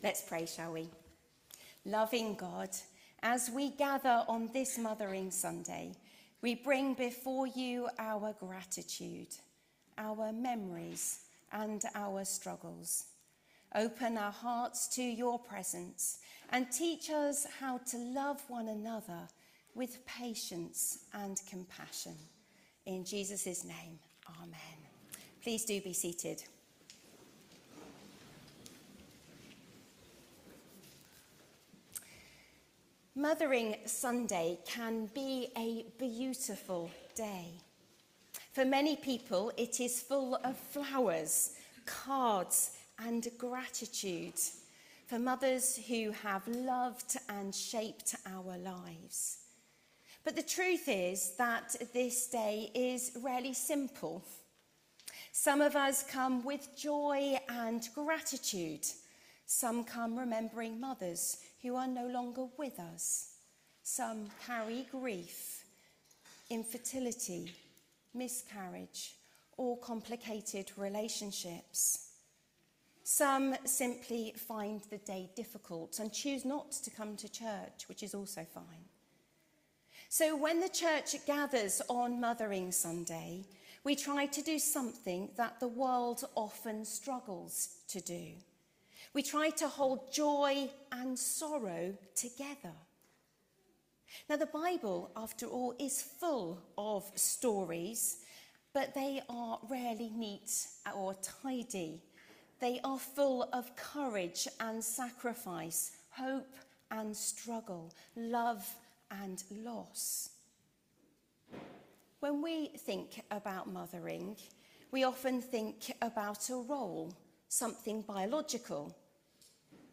HomeSermonsThe whole church must mother